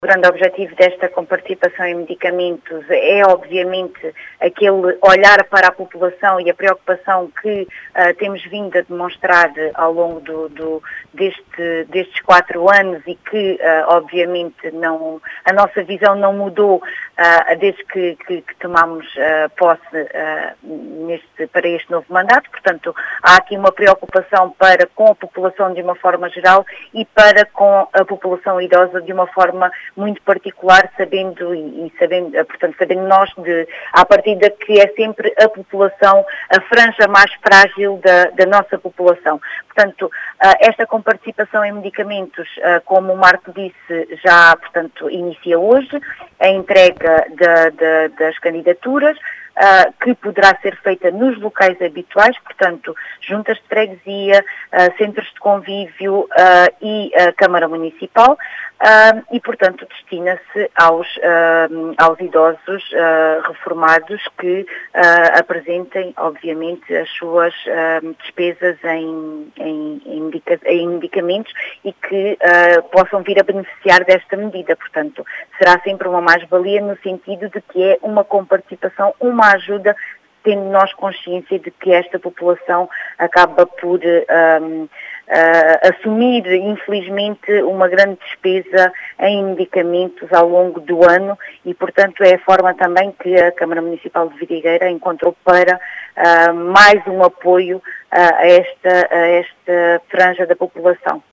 As explicações foram deixadas na Rádio Vidigueira, por Ana Patricia Marreiros, vereadora da Câmara Municipal de Vidigueira.